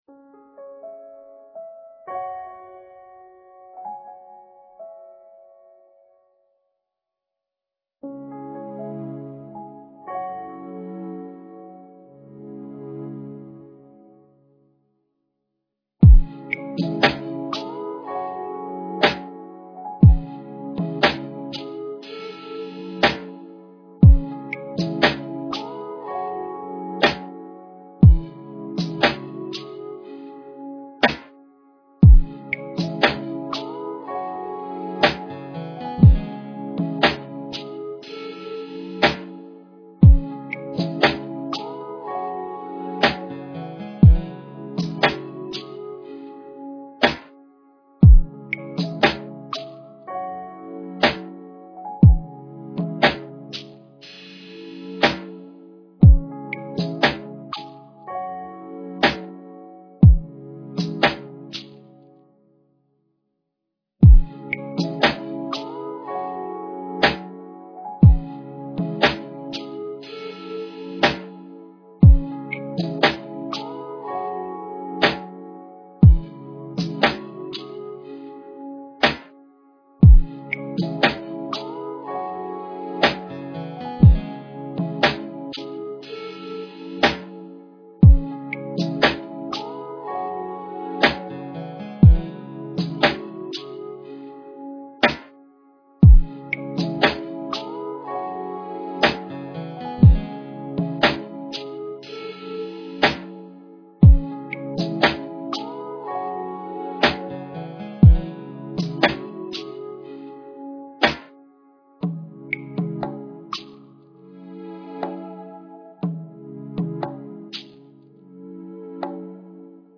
R&B and Hip Hop Beats for Cheap
Tags: r&b